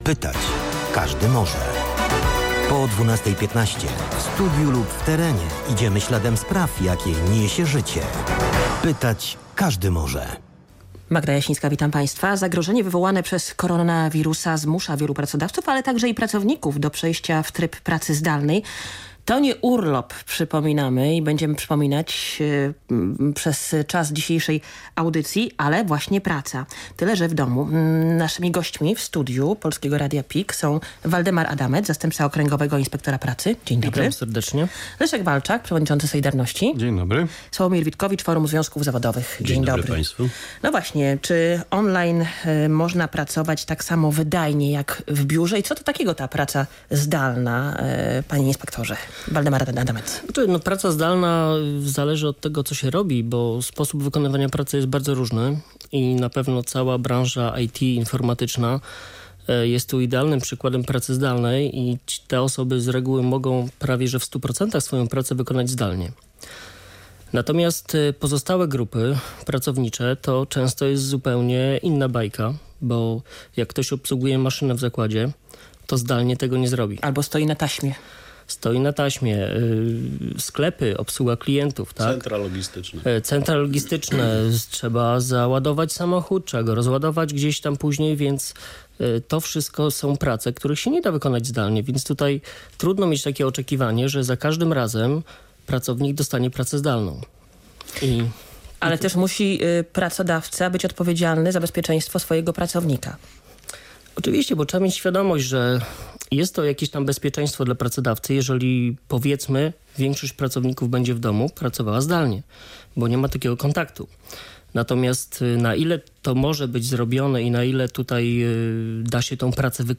W audycji Radia PKI "Pytać każdy może" przedstawiciele związków zawodowych i eksperci Okręgowego Inspektoratu Pracy dyskutują o pracy zdalnej, prawie do wynagrodzenia pomimo przymusowego urlopu i innych zmianach wprowadzonych w związku z epidemią koronawirusa.